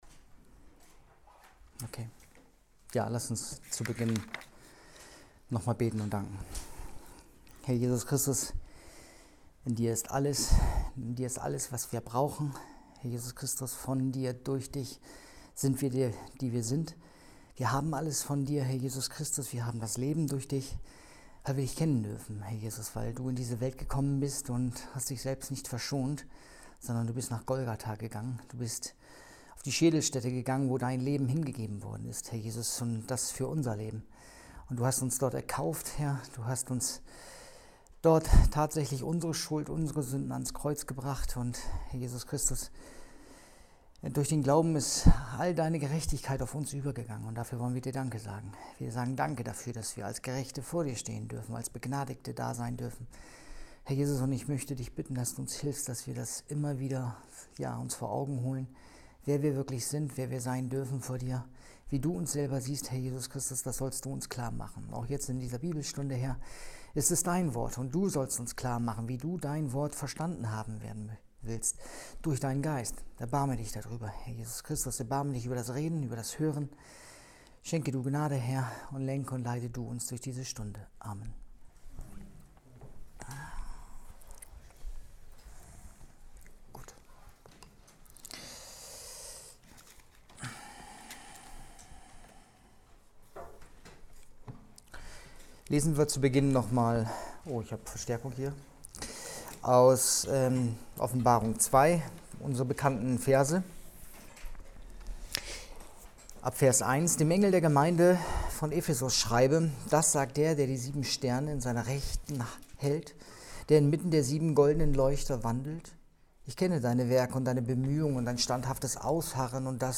Bibelstunde_11.06.2020